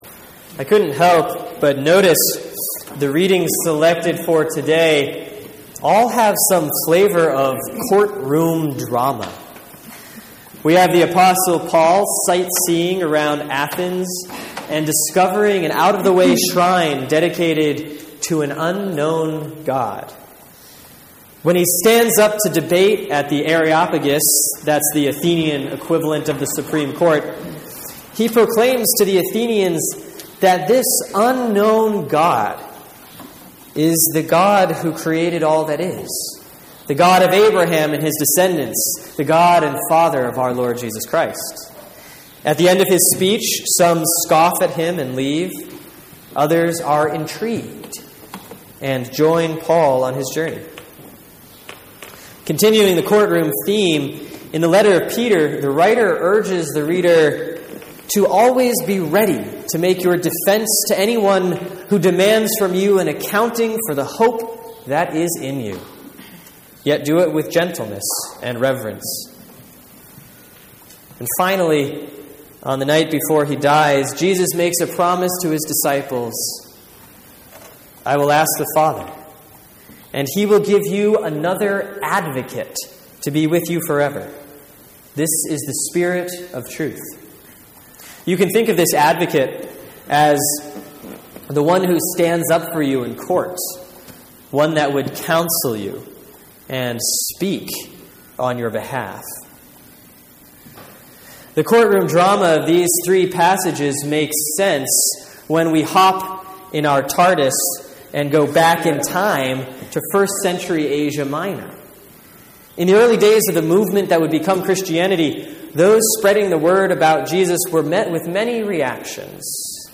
Sermon for Sunday, June 14, 2015 || Proper 6B || Mark 4:26-34